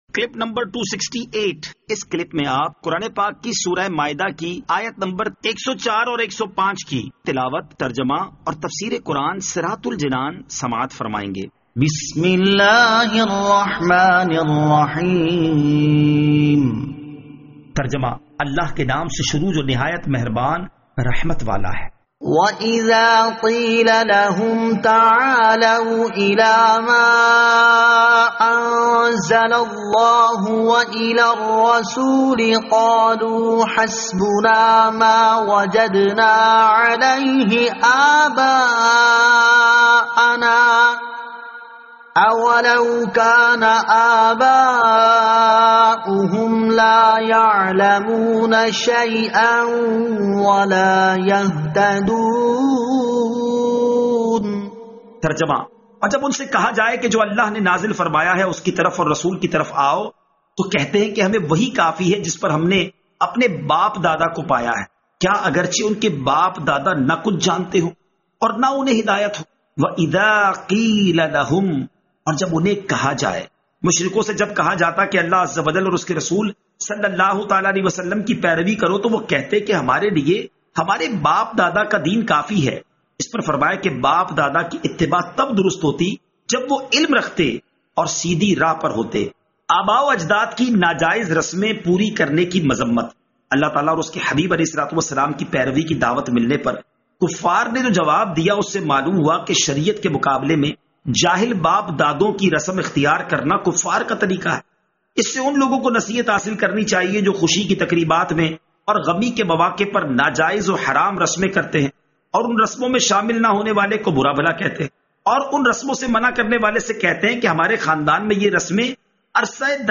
Surah Al-Maidah Ayat 104 To 105 Tilawat , Tarjama , Tafseer
2020 MP3 MP4 MP4 Share سُوَّرۃُ ٱلْمَائِدَة آیت 104 تا 105 تلاوت ، ترجمہ ، تفسیر ۔